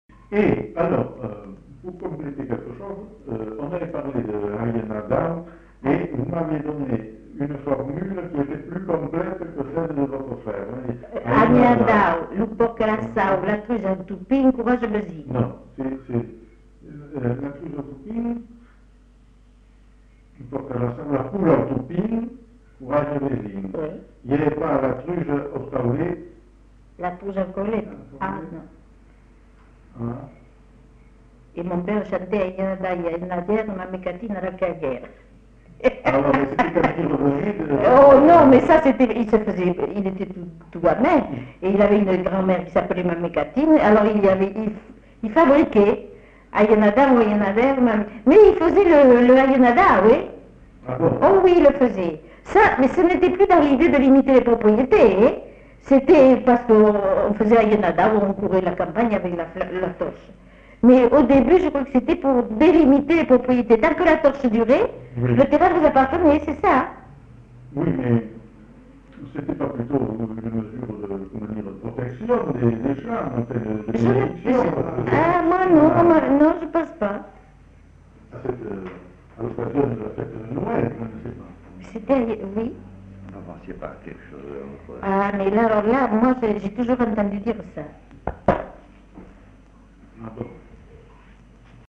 Lieu : La Réole
Genre : forme brève
Type de voix : voix de femme
Production du son : récité